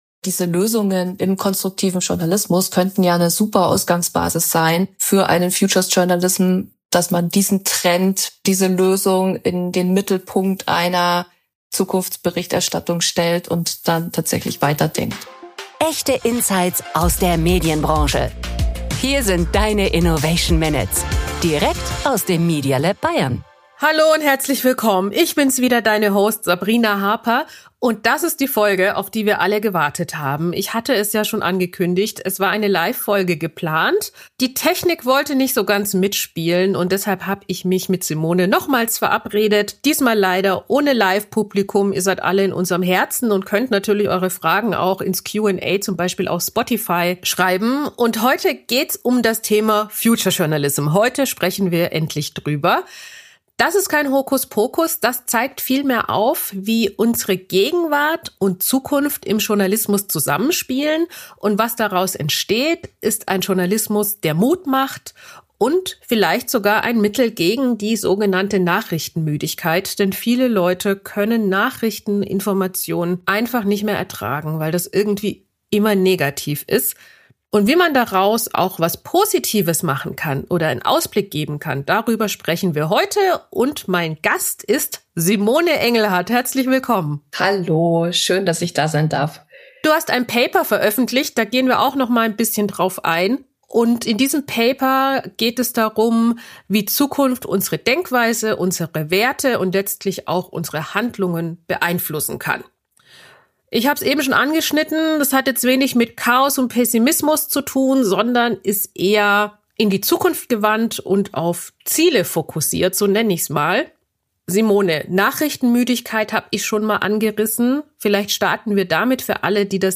In dieser Folge geht es um Future Journalism – ein Ansatz der gegen Nachrichtenmüdigkeit wirken könnte. Ein Gespräch